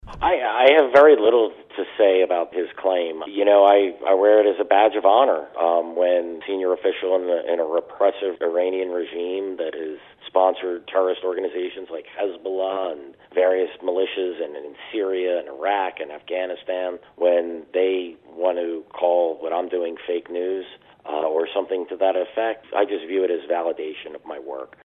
Interview: CIA Files On Cooperation Between Iran And Al-Qaeda